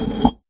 stone.ogg